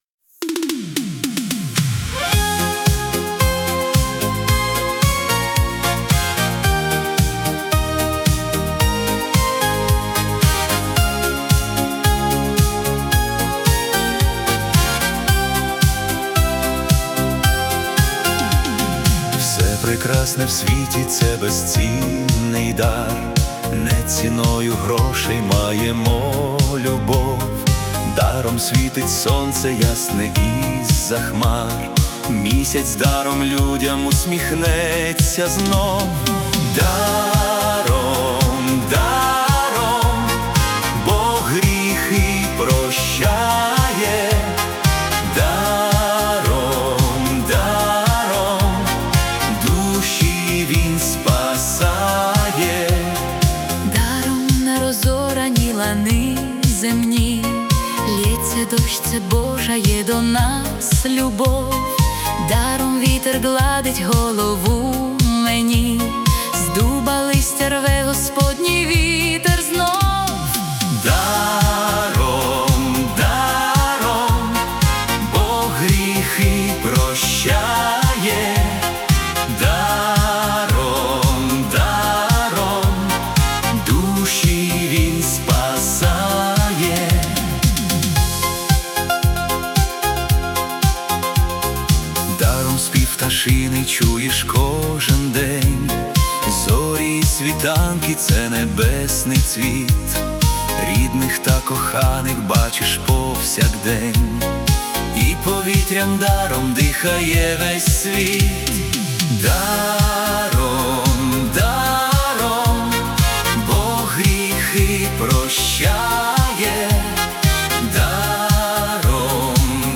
Світла християнська пісня про безцінні дари нашого життя.